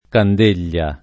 [ kand % l’l’a ]